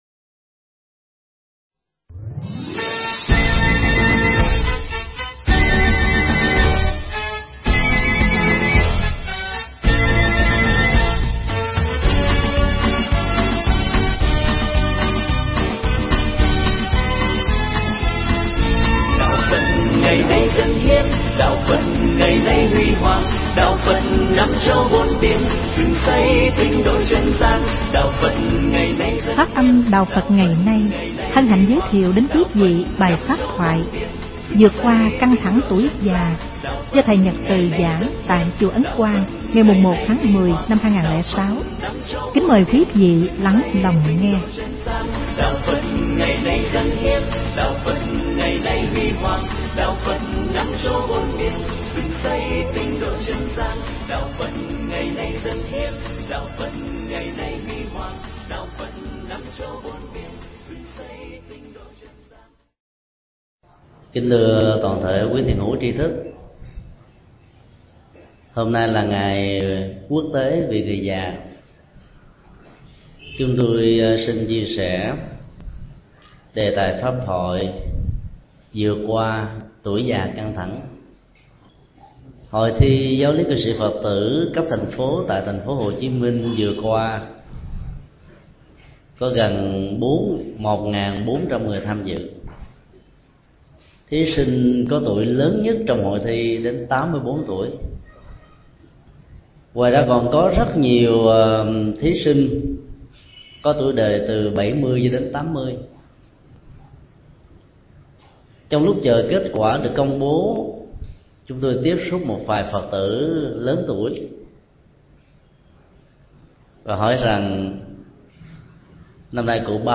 pháp thoại Vượt Qua Căng Thẳng Tuổi Già
giảng tại Chùa Ấn Quang